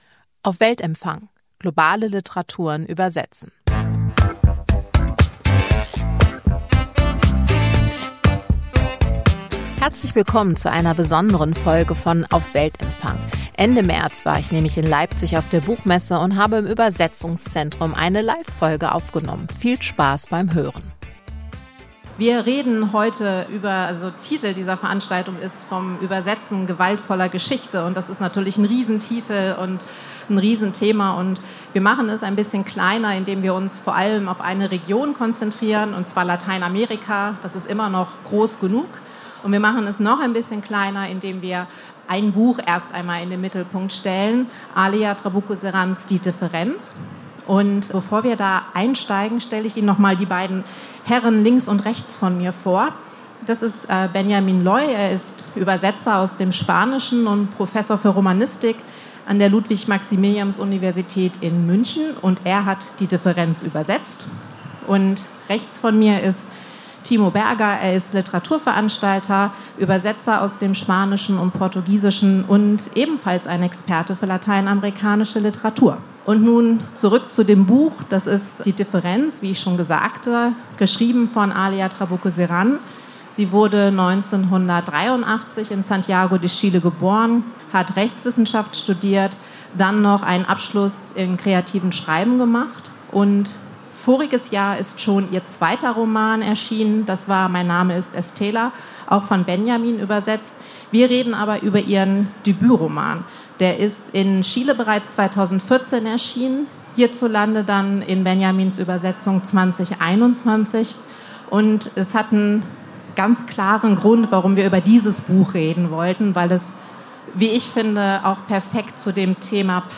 Gewaltvolle Geschichte übersetzen – Ein Live-Podcast von der Leipziger Buchmesse ~ Litprom auf Weltempfang - Globale Literaturen übersetzen Podcast